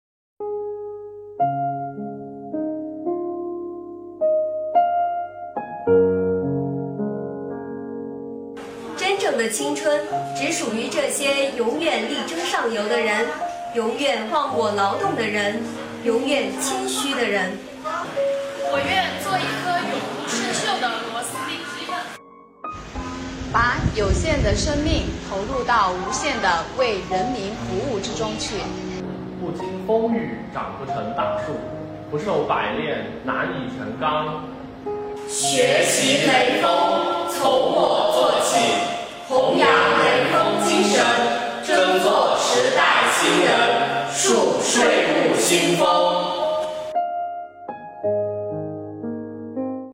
【学雷锋志愿服务月】港城税务青年带您重温雷锋语录（二）
今年是第60个“学雷锋纪念日”和第3个“广西志愿者日”，为深入学习贯彻党的二十大精神和习近平总书记对深入开展学雷锋活动的重要指示精神，进一步培育和践行社会主义核心价值观，大力弘扬雷锋精神，在全市税务系统形成学习雷锋精神的良好风尚，防城港市税务局系统团委组织开展“读雷锋语录 学雷锋精神”活动，下面由防城港市防城区税务局的青年们带我们一起重温雷锋经典语录~